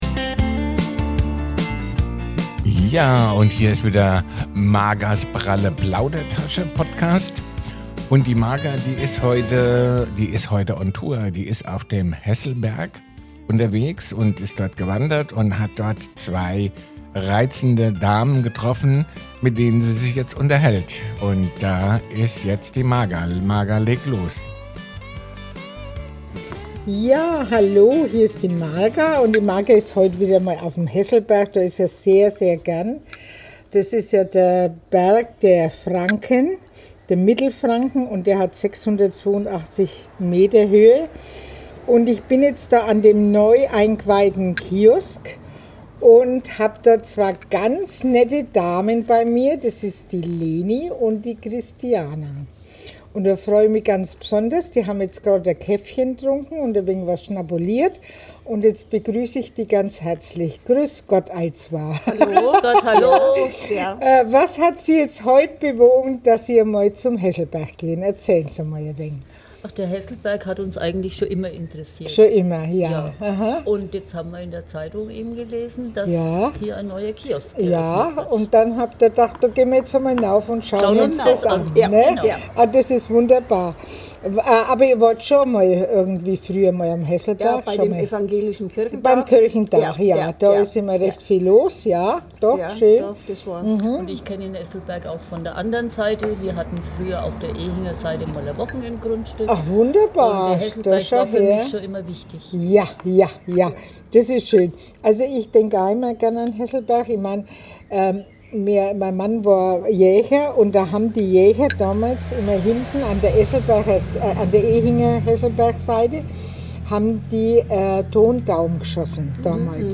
Unterwegs mit zwei charmanten Damen auf Frankens Hausberg. Erinnerungen, Begegnungen und ein bisschen Nostalgie warten auf euch.